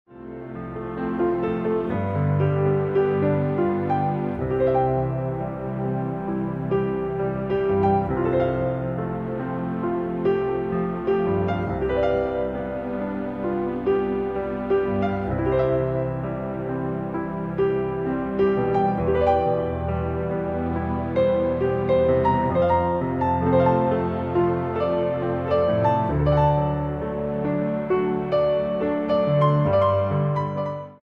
Hum along with our easy-listening country piano music CDs.